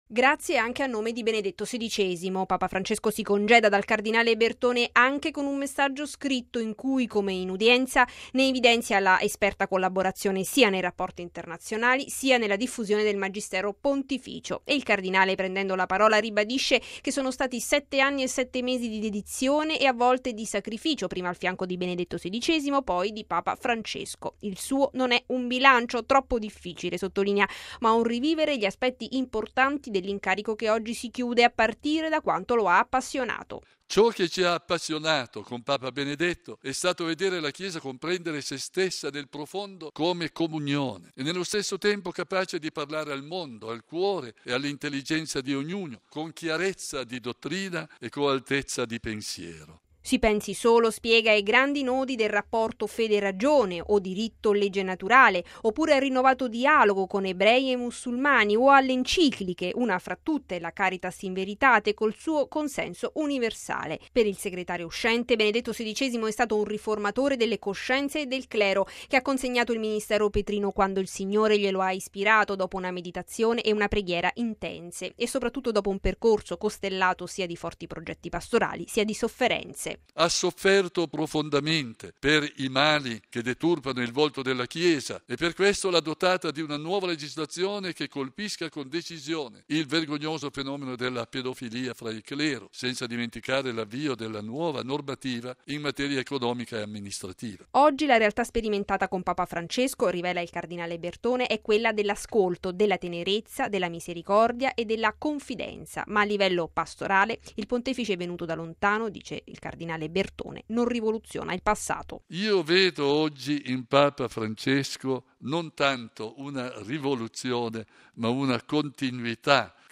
E il cardinale, prendendo la parola, ribadisce che sono stati sette anni e sette mesi di dedizione e a volte di sacrificio prima al fianco di Benedetto XVI poi di Papa Francesco.